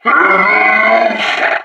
This is an audio clip from the game Team Fortress 2 .
Mercenary_Park_Yeti_statue_growl2.wav